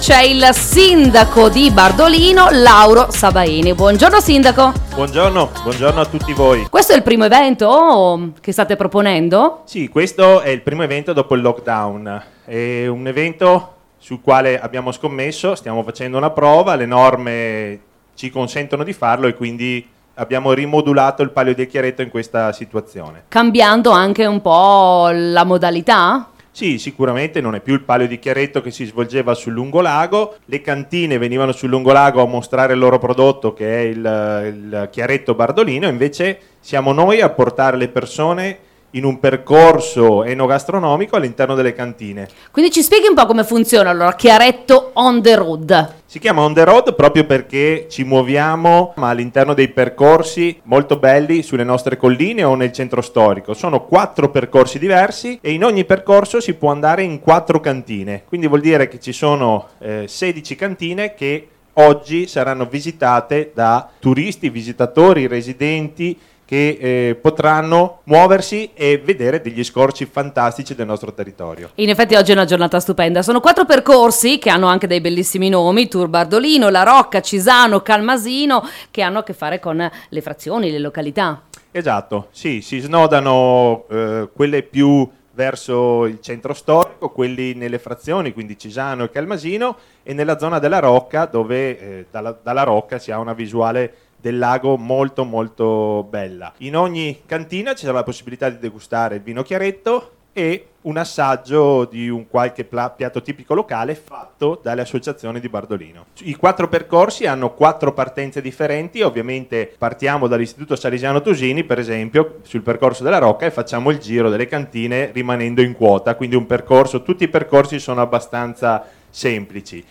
Al microfono di Radio Pico:
il sindaco di Bardolino, Lauro Sabaini